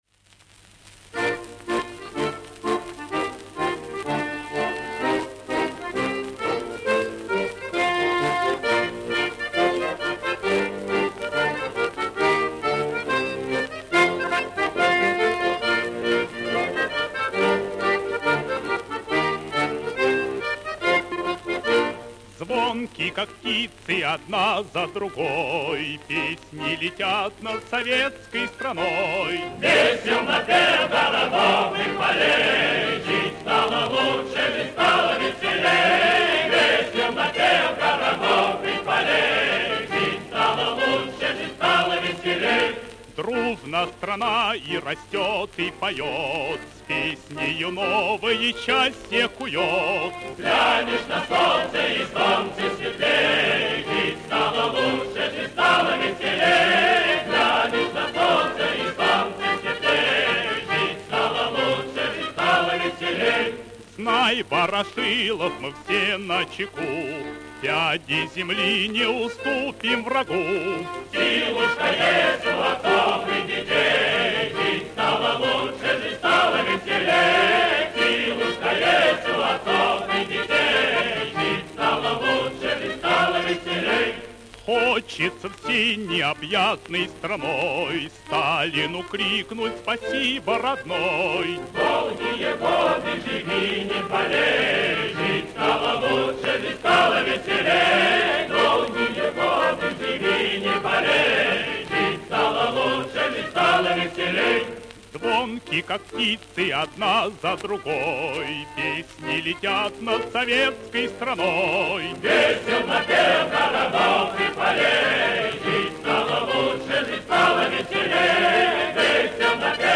Запись 1936 года
Краснознаменный ансамбль Красноармейской песни и пляски СССР п/у проф. А. В. Александрова
Прослушивая первое исполнение песни "Жить стало лучше", записанное под гармонику на пластинку с № 4506, мы уже вполне улавливаем родственную связь между ней и гимном страны.